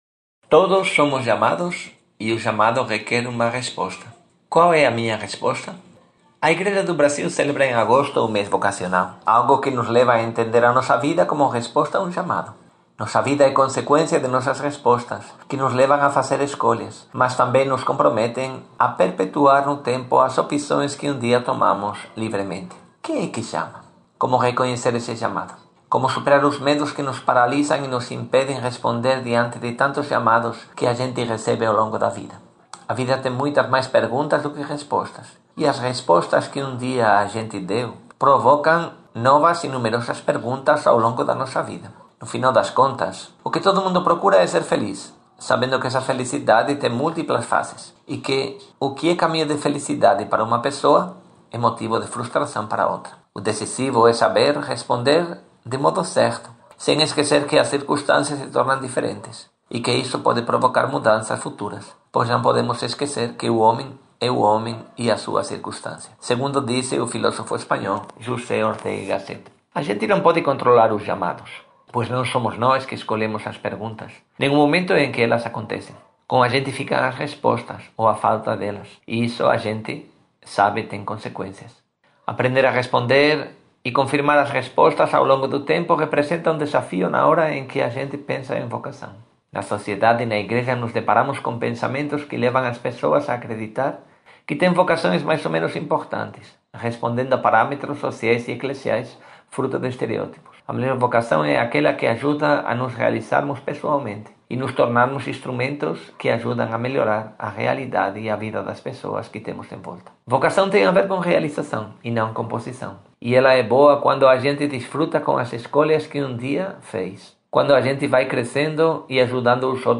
Editorial: Todos somos chamados, e o chamado requer uma resposta.